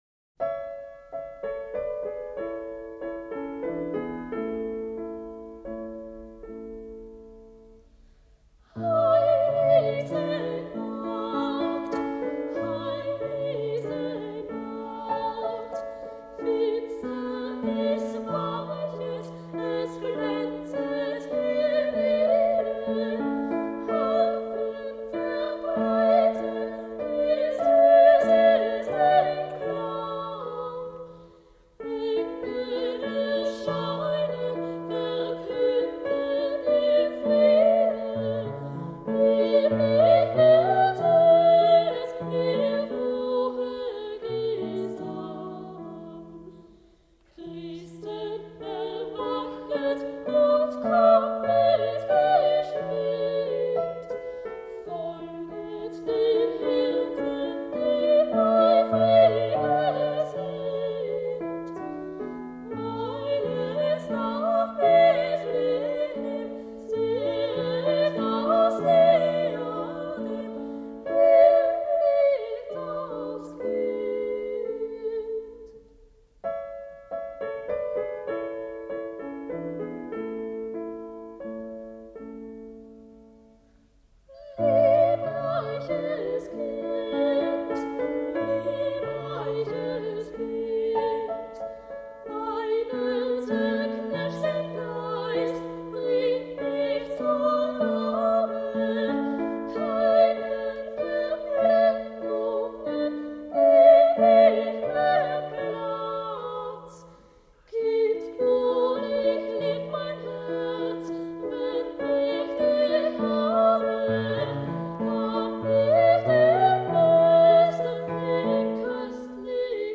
Die Wiener Sängerknaben - 21.12.